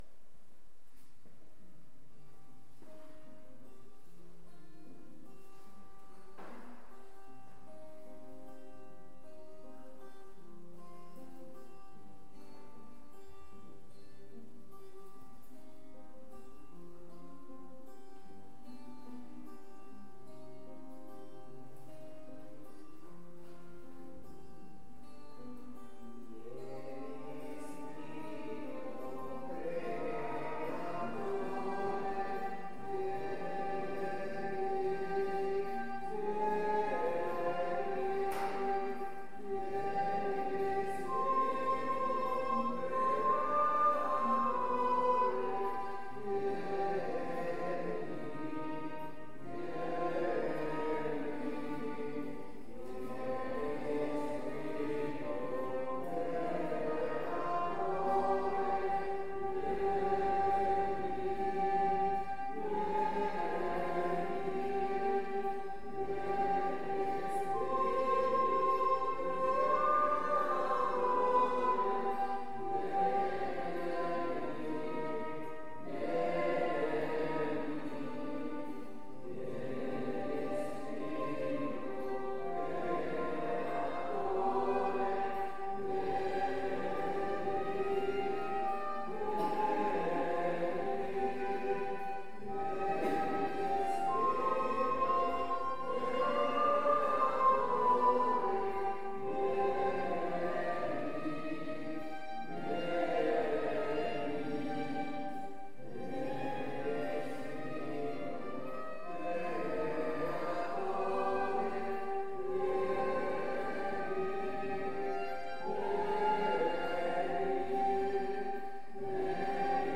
Pregària de Taizé a Mataró... des de febrer de 2001
Parròquia de la Mare de Déu de Montserrat - Diumenge 28 de febrer de 2016
Vàrem cantar...